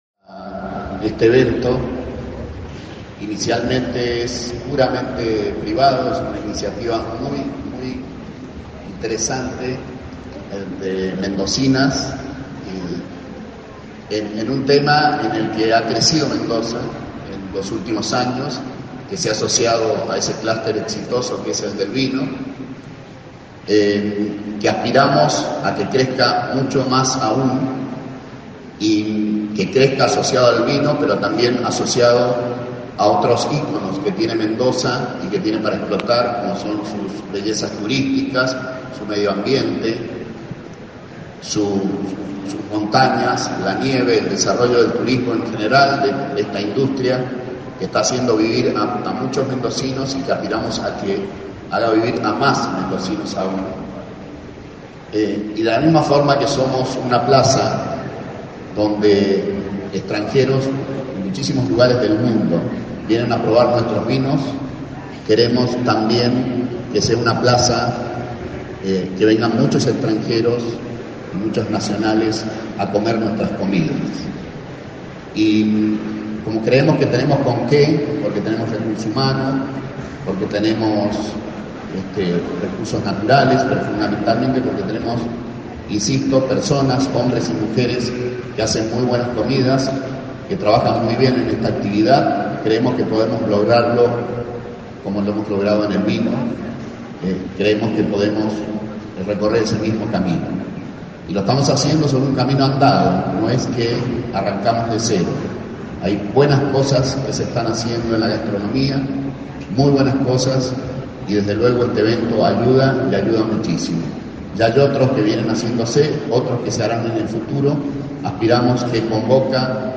El Gobernador Alfredo Cornejo participó esta tarde en la inauguración del Festival Gastronómico ConBoca.